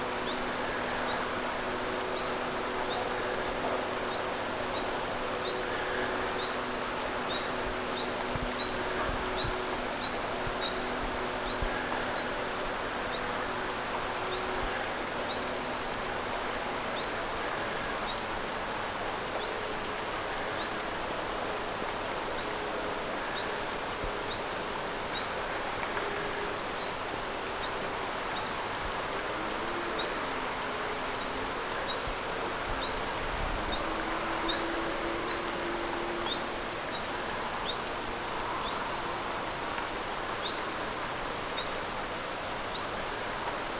Päätellen niiden hieman erilaisesta ääntelytavasta.
Näissä äänitteissä taitaa olla se hyit-versiota käyttänyt.
hyit-tiltaltti_saaressa.mp3